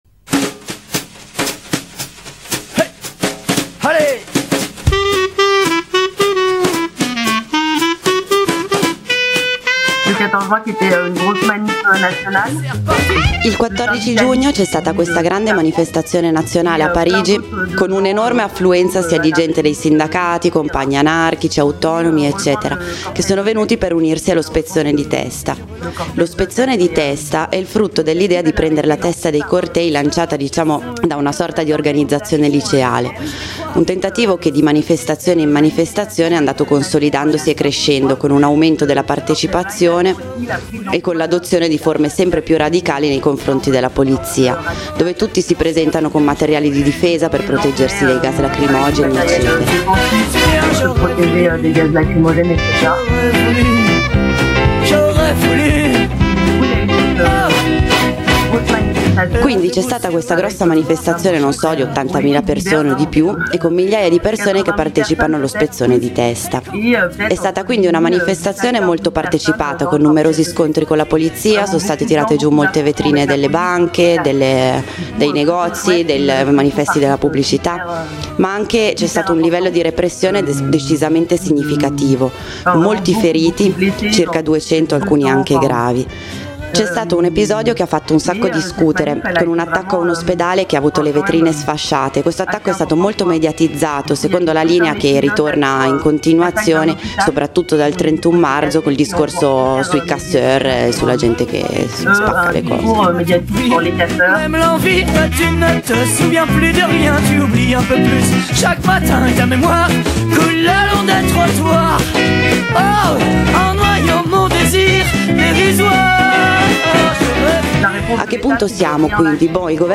Dopo la grande manifestazione del 14 giugno scorso, è difficile comprendere in quale direzione possa evolversi la situazione: da un lato, la fermezza del governo e la brutalità della sua polizia; dall’altro, la CGT presa dalla doppia esigenza di non perdere né la faccia, né il controllo della situazione; infine il ripetuto e incontrollabile tracimare degli “spezzoni di testa”, Alcune considerazioni da parte di una compagna di Parigi.